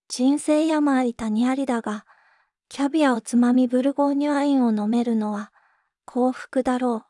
voicevox-voice-corpus
voicevox-voice-corpus / ROHAN-corpus /WhiteCUL_かなしい /ROHAN4600_0005.wav